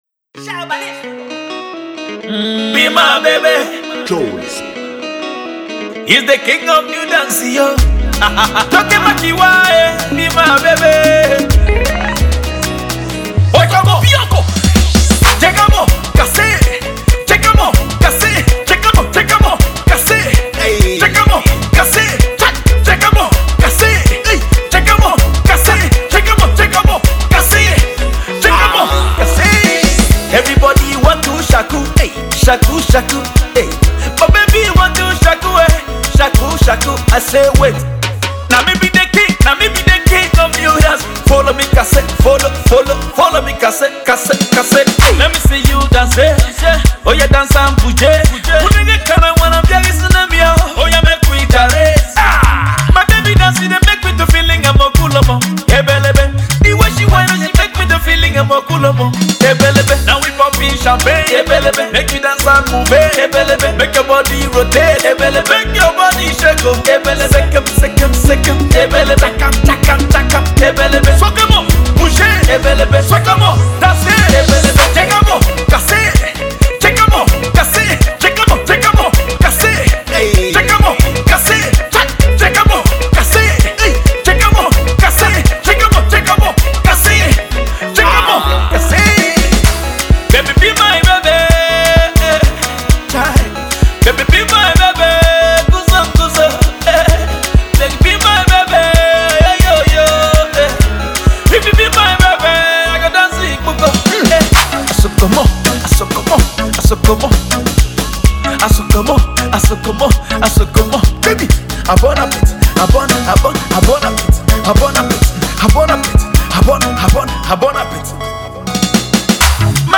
This uptempo song is definitely going to reign in the clubs.